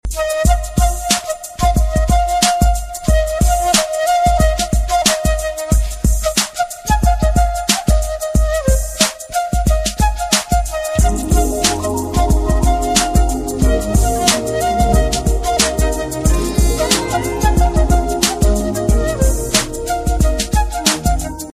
love ringtonemelody ringtoneromantic ringtonetamil ringtone
best flute ringtone download